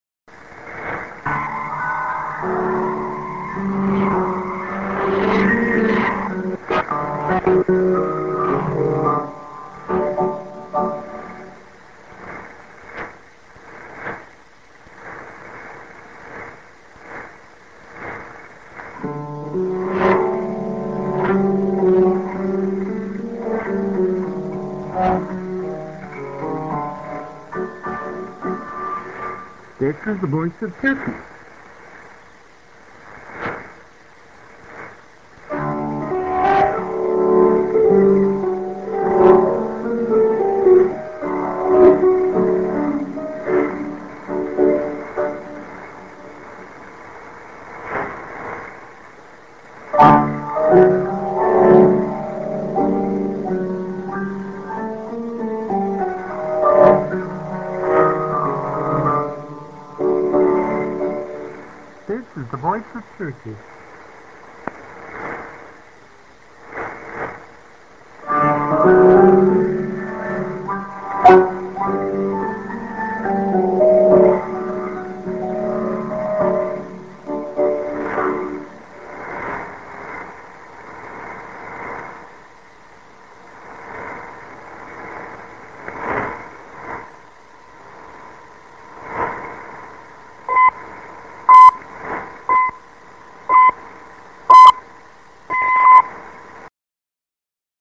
a:　IS+ID(man+women):Rep->TS